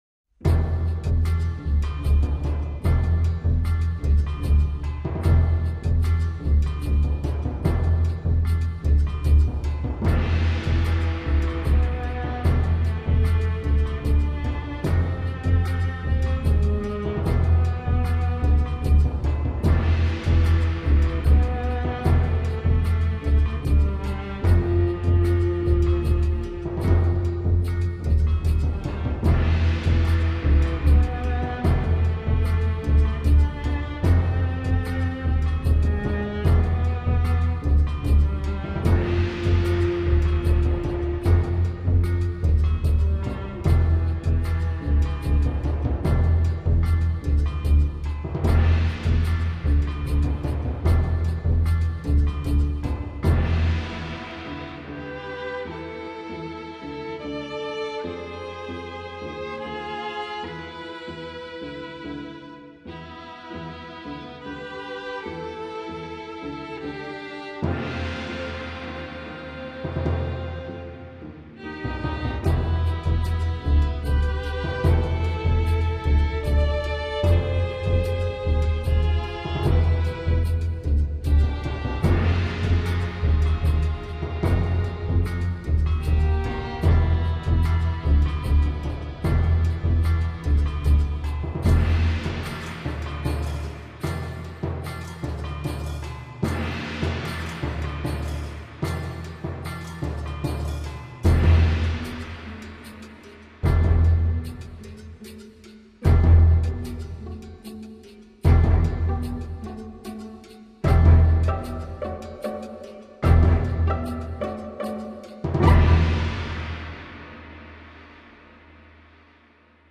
Жанр обощенно зовется стимпанк-мьюзик.